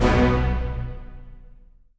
ORChit47(L).wav